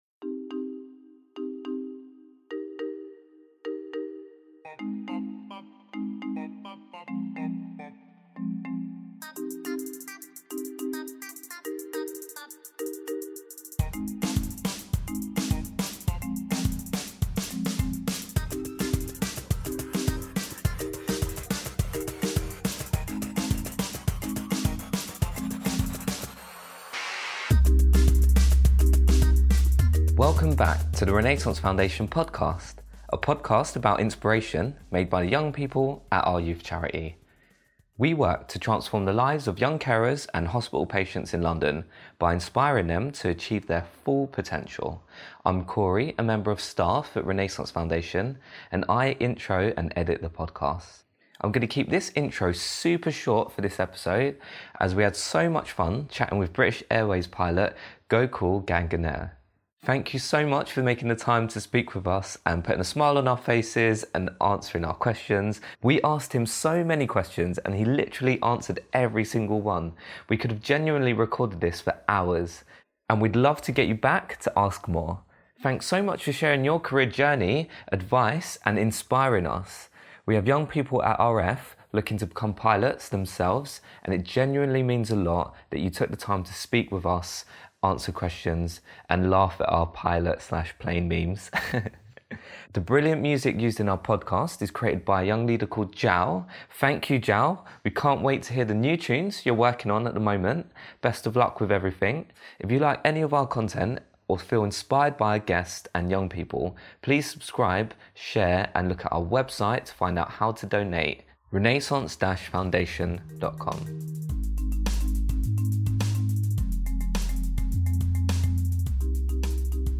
We asked him so many questions and he literally answered every single one, we could’ve genuinely recorded this for hours!